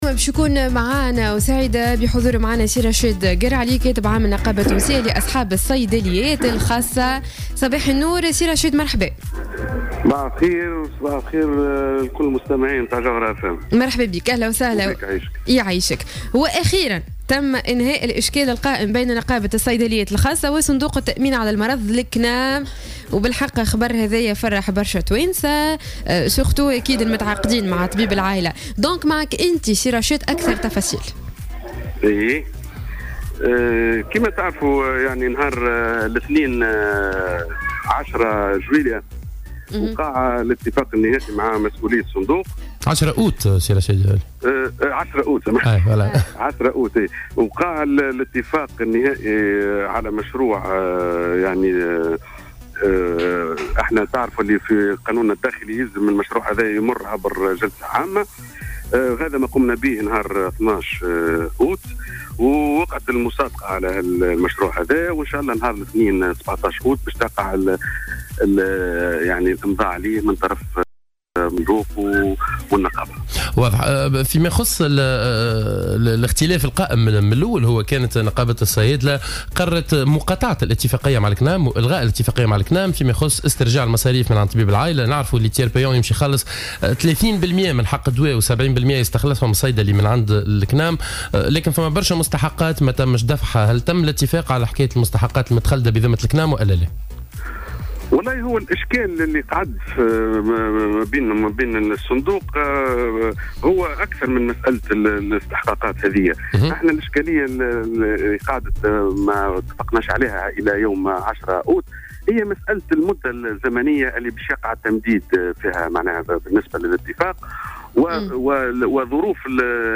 lors de son intervention sur les ondes de Jawhara FM